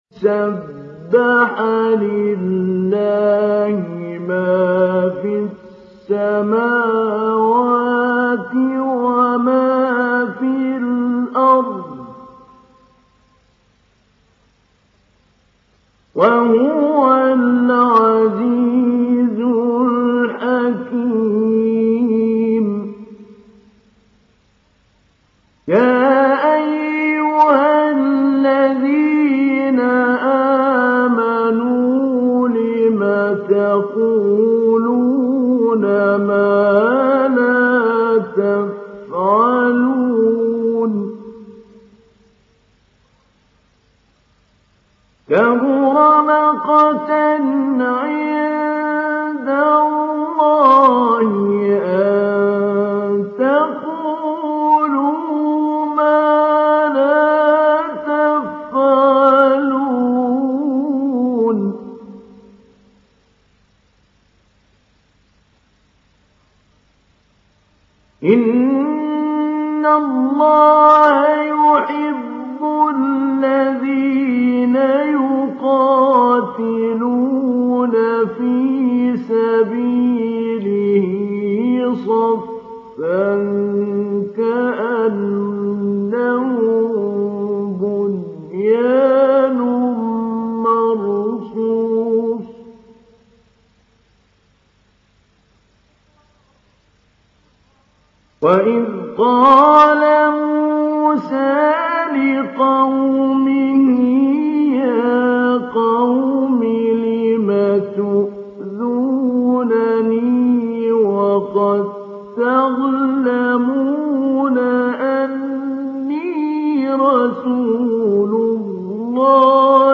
دانلود سوره الصف محمود علي البنا مجود